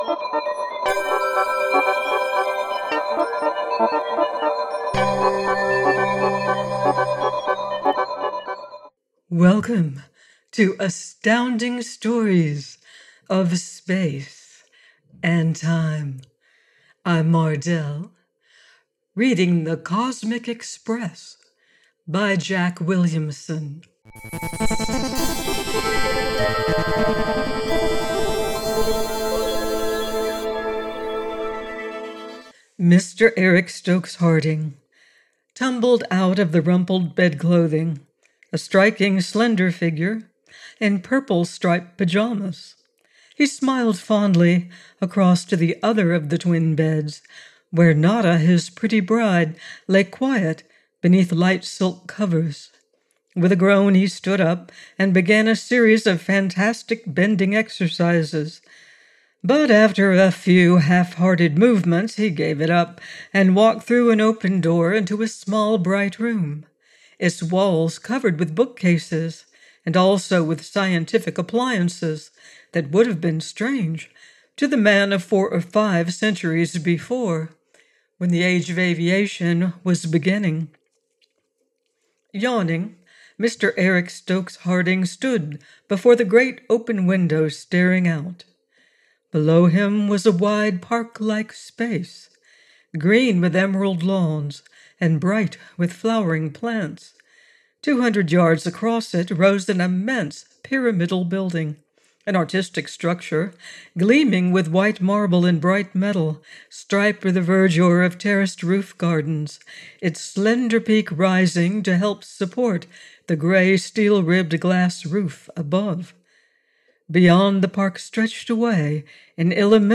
The Cosmic Express by Jack Williamson - AUDIOBOOK